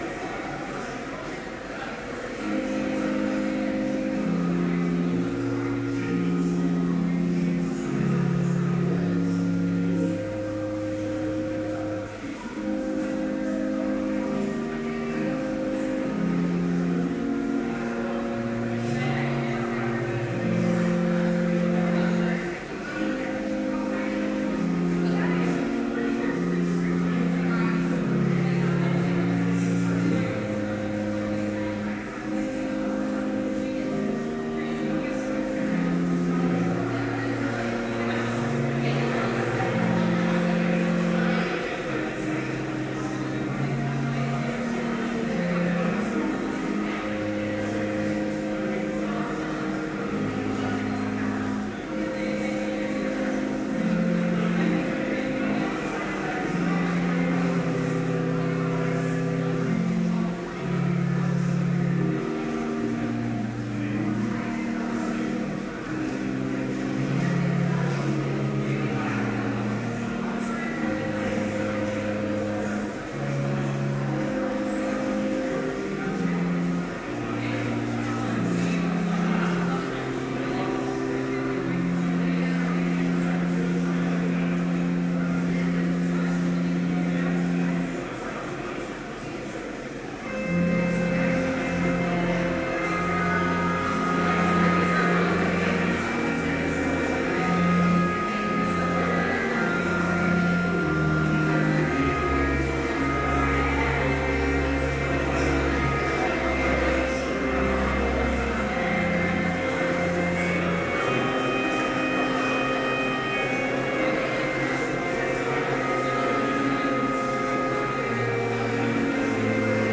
Complete service audio for Chapel - September 5, 2013